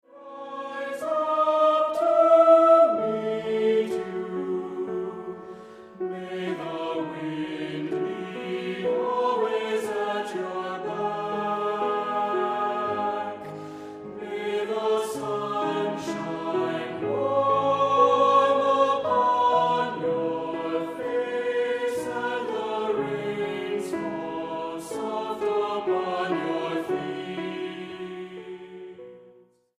Voicing: Two-part equal; Solo